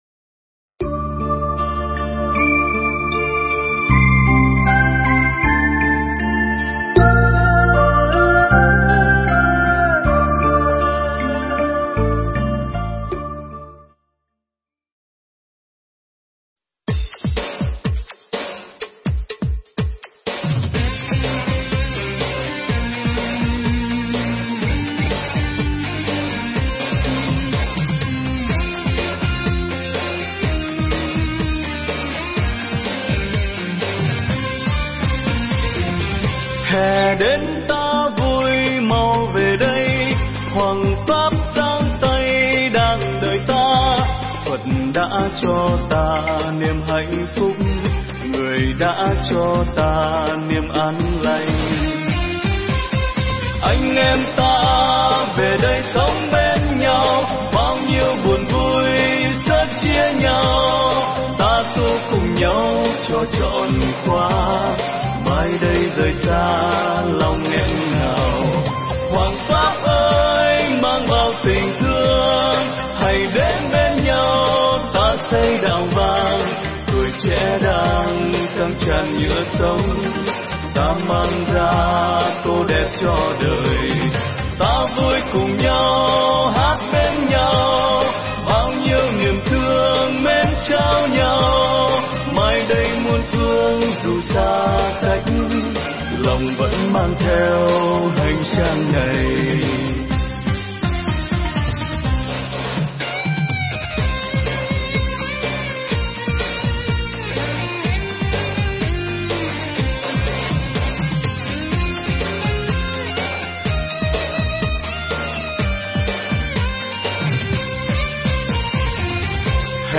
Nghe Mp3 thuyết pháp Phước Duyên
Mp3 pháp thoại Phước Duyên